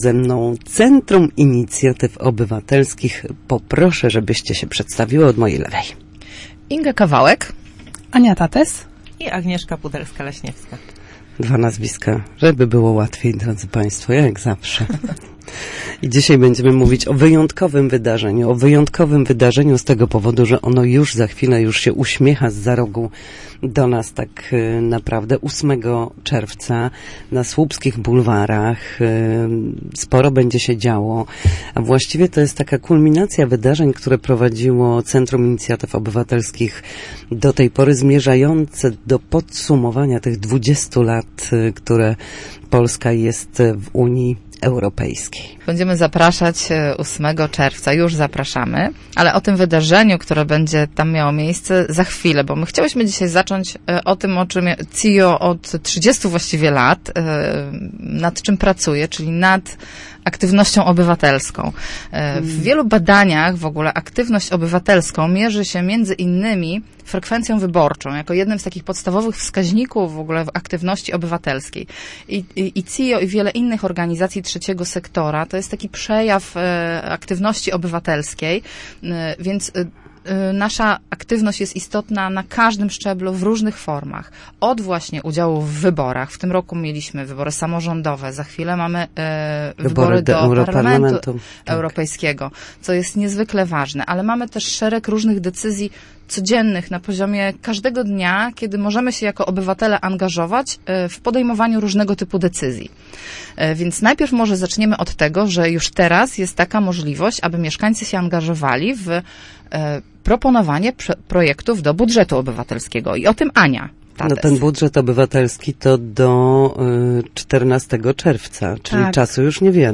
w Studiu Słupsk Radia Gdańsk były przedstawicielki Centrum Inicjatyw Obywatelskich ze Słupska. Na naszej antenie panie poruszały tematy związane między innymi z Unią Europejską, także w kontekście 20 lat działań społecznych, lokalnych inicjatyw jak budżet obywatelski czy wspólnych działań NGO w regionie słupskim.